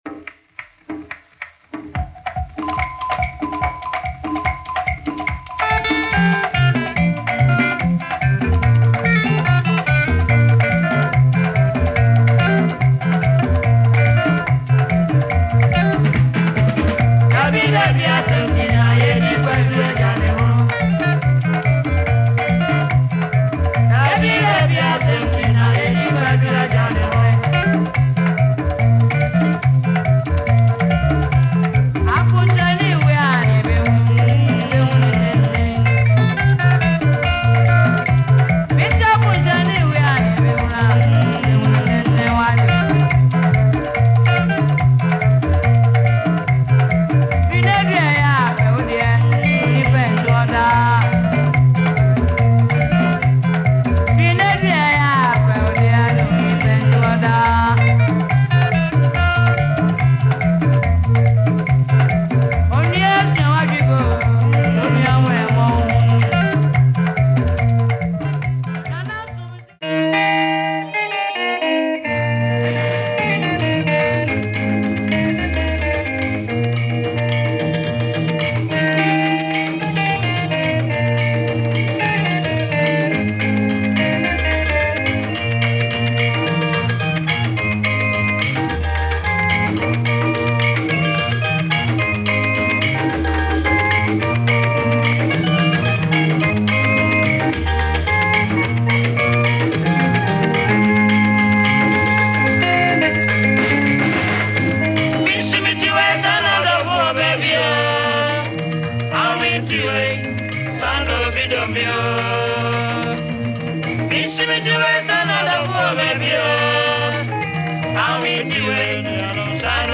Psychedelic highlife